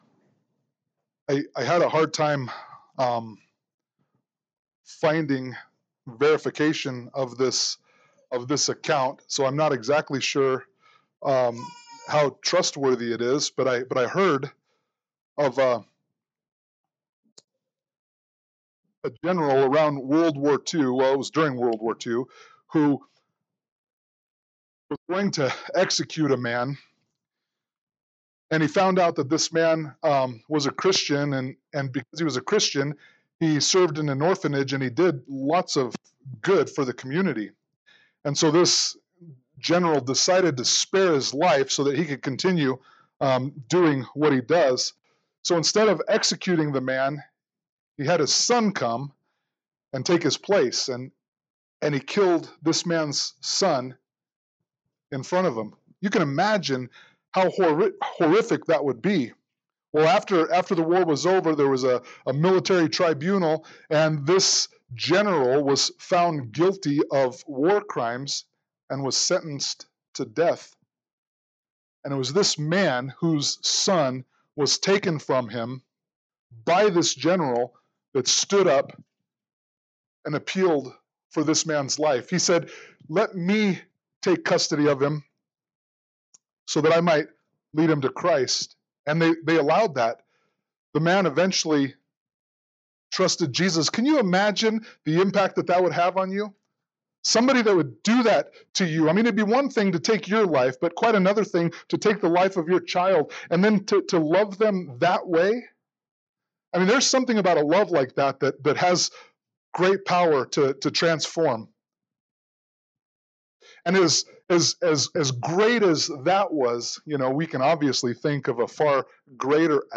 1 John 4:7-16 Service Type: Sunday Morning Worship « 1 John 4:1-6